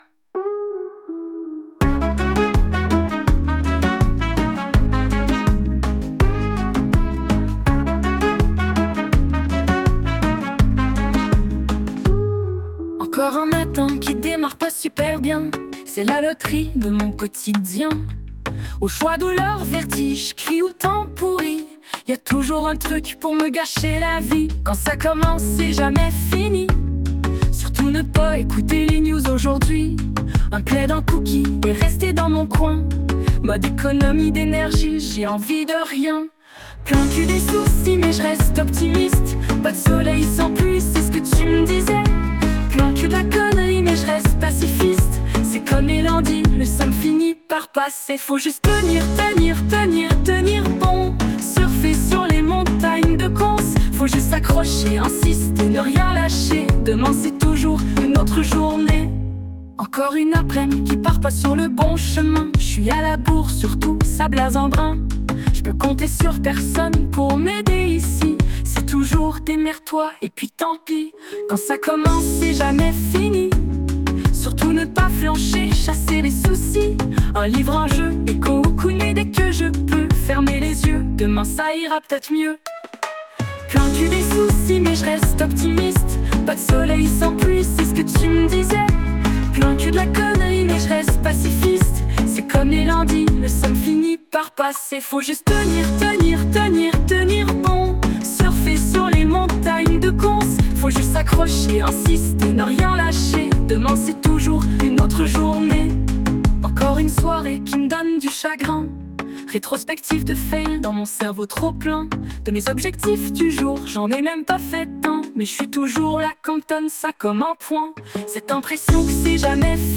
… et j’ai découvert SUNO… Un site où on crée de la musique avec l’I.A. en lui donnant un prompt, ou des lyrics.